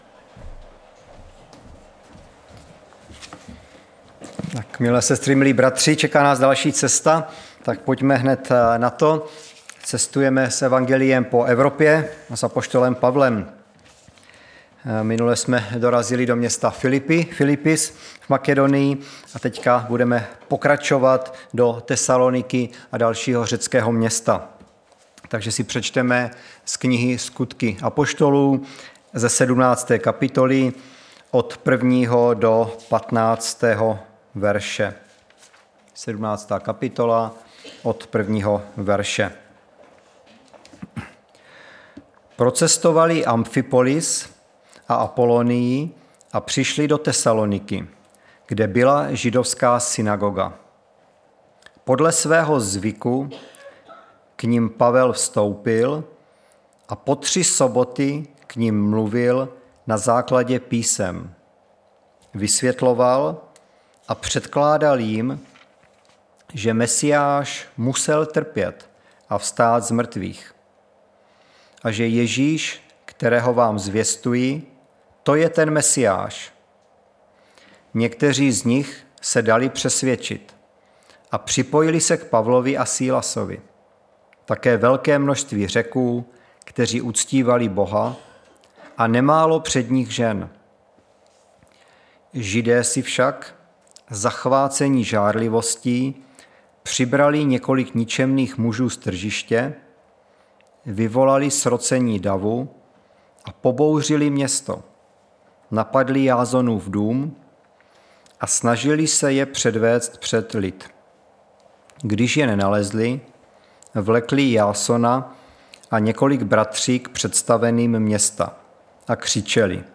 Středeční vyučování